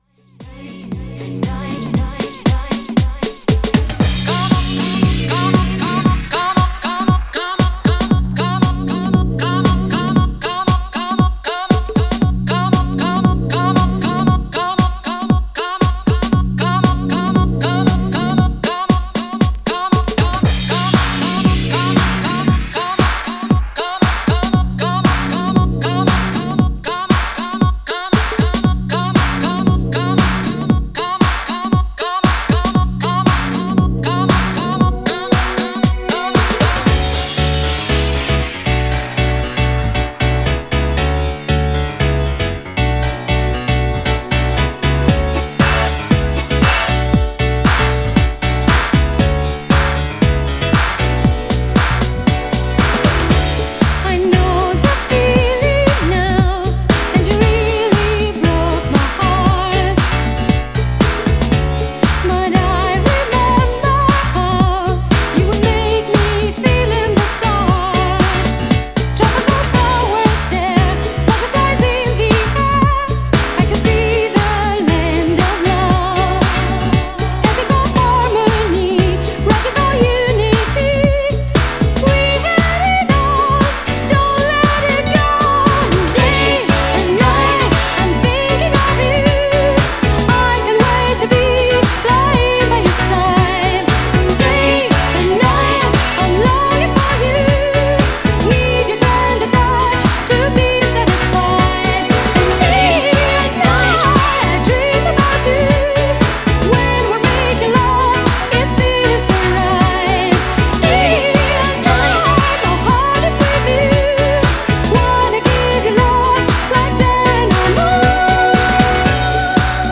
高らかに伸びるサビが印象的なグランド好きの間ではもはや大定番な曲。
GENRE R&B
BPM 106〜110BPM